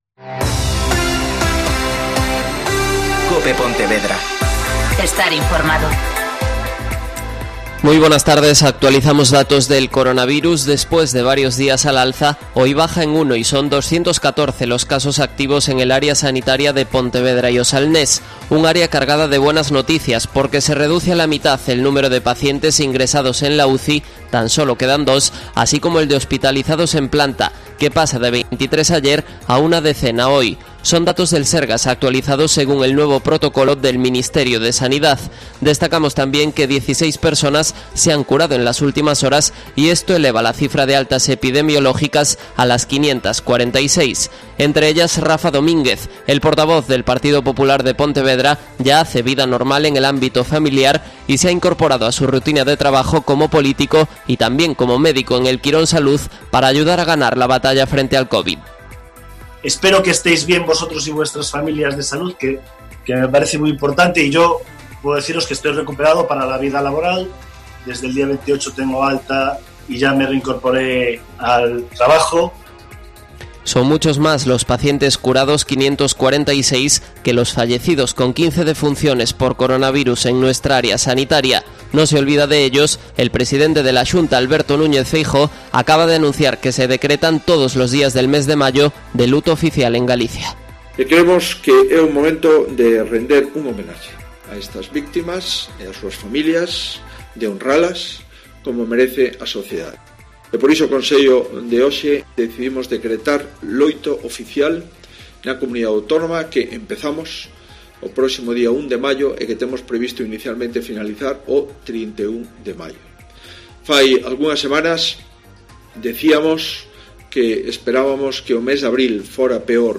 Mediodía COPE Pontevedra (Informativo 14:20h )